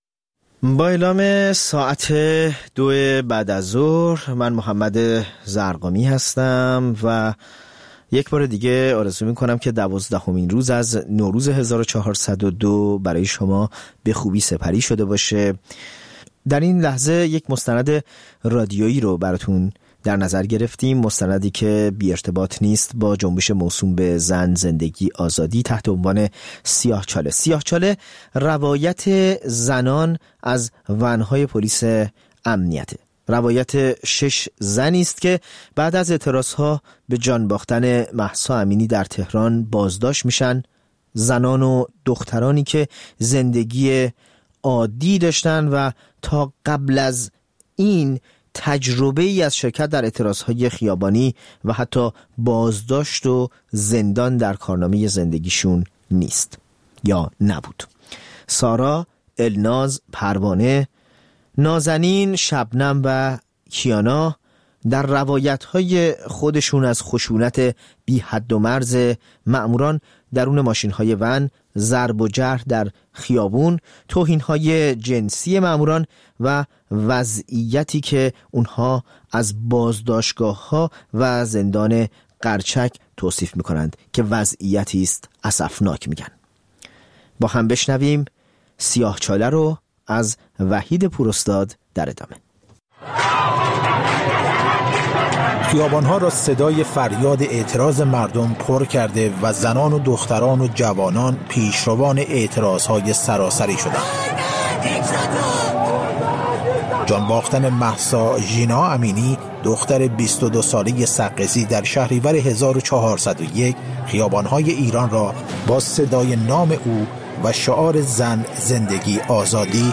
مستند رادیویی: سیاه‌چاله؛ روایت زنان از و‌ن‌های پلیس